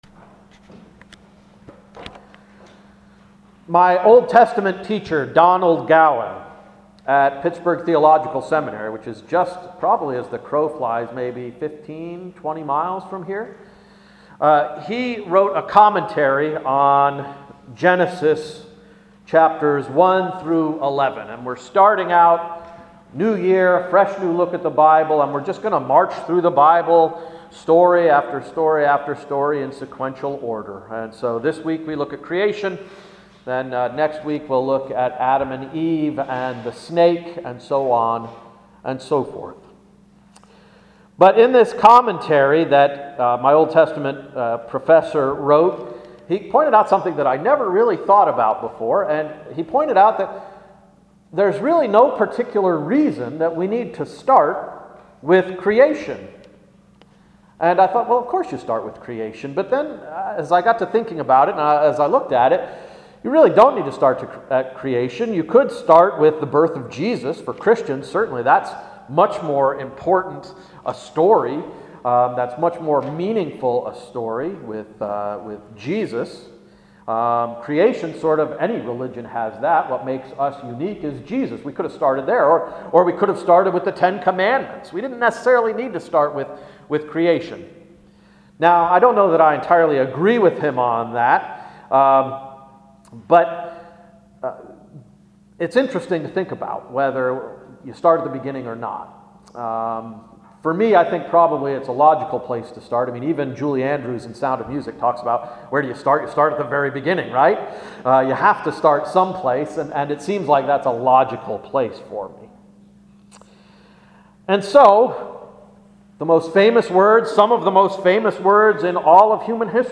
Year of the Bible–2016 Sermon One-“The Creation”